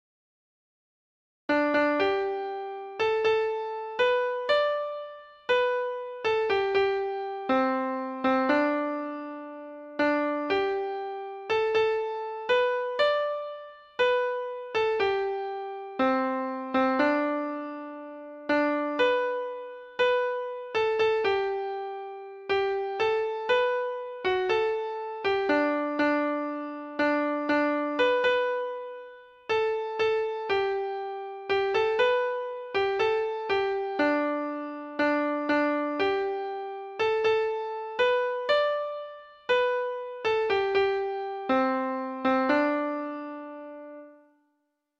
Folk Songs from 'Digital Tradition' Letter ( (unknown title)